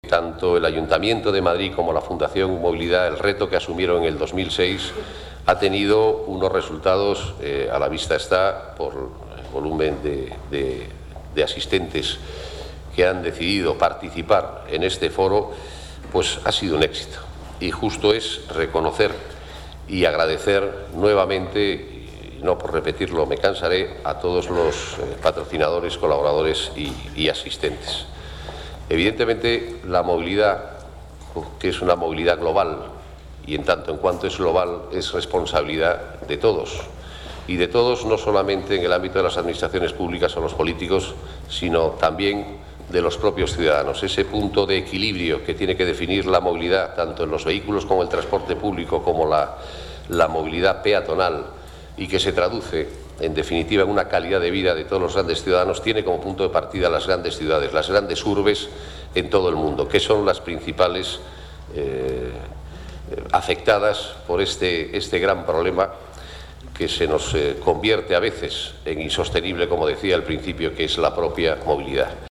Nueva ventana:Declaraciones de Fernando Autrán, coordinador general de Movilidad